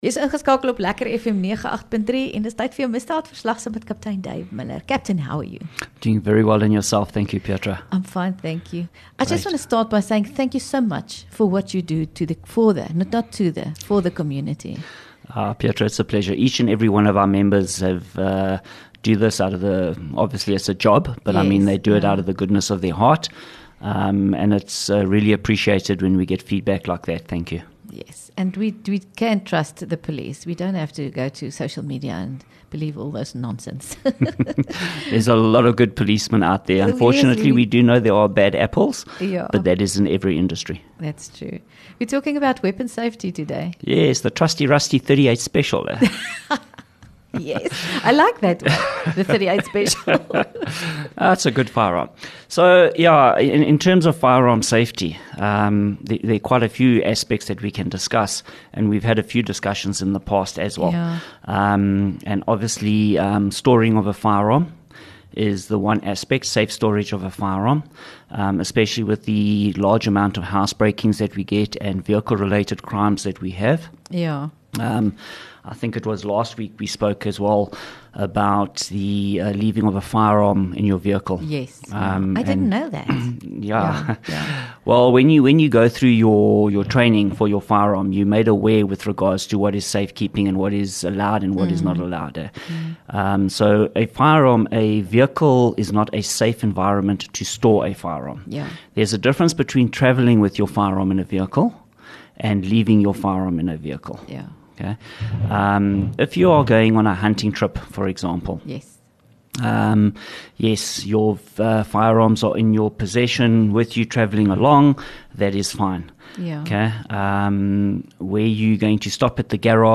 LEKKER FM | Onderhoude 22 Oct Misdaad Verslag